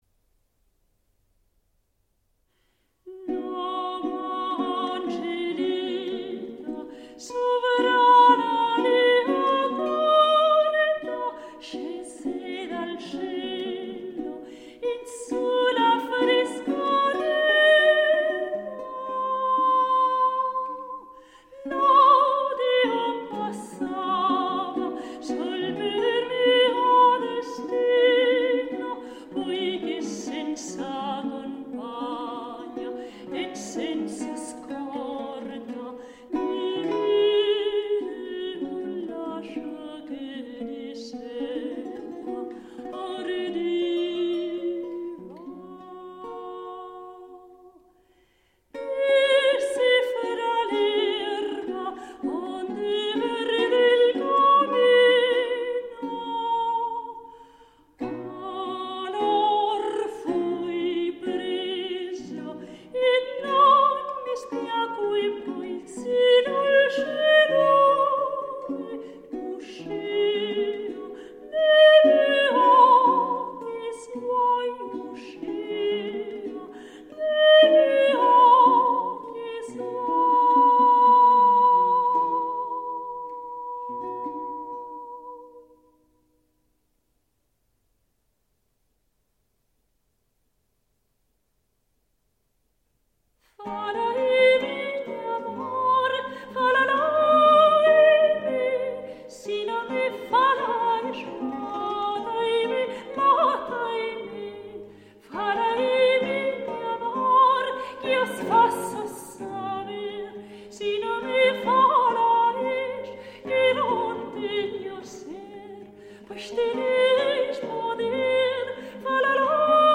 Villancico
~1400 - ~1800 (Renaissance)